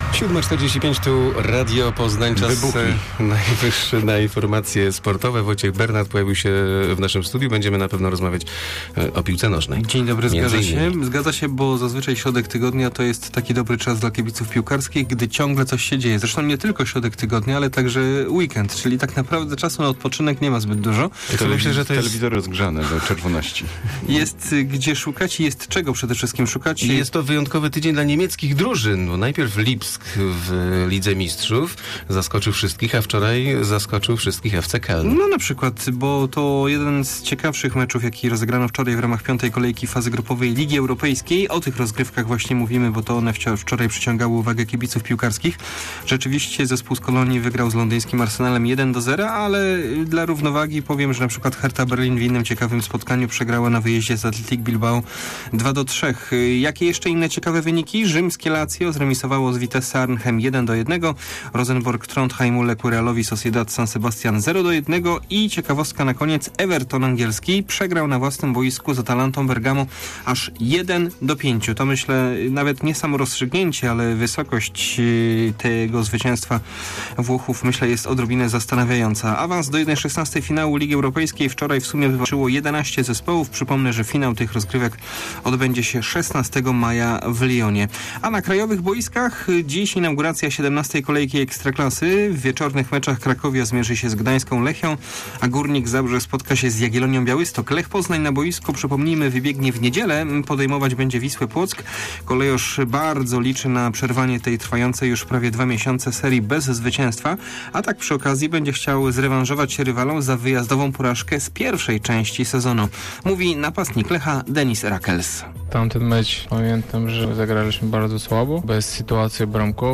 24.11 serwis sportowy godz. 7:45